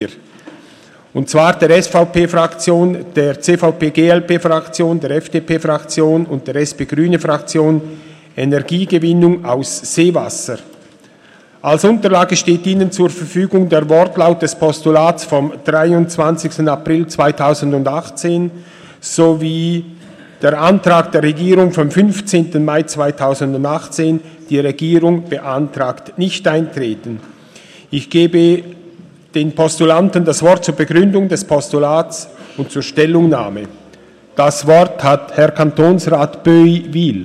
Session des Kantonsrates vom 26. bis 28. November 2018